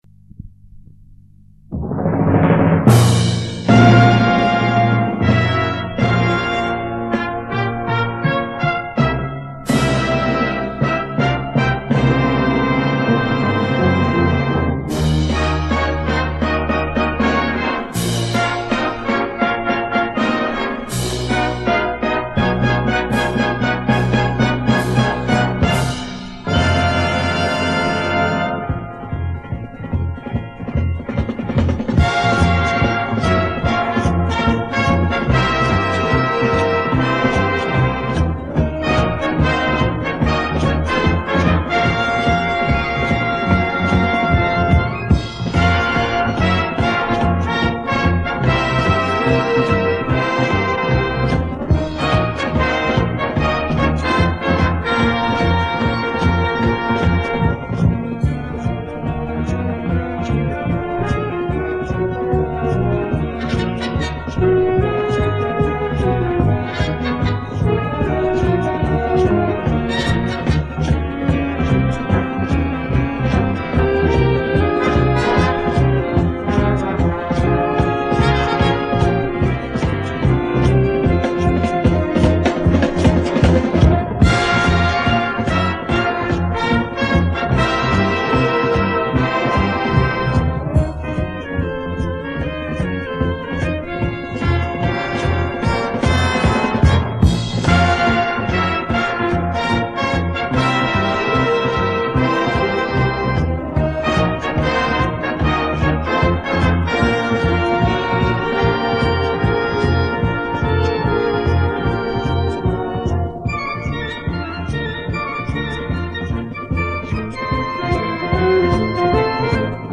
Gattung: Konzertstück
A4 Besetzung: Blasorchester PDF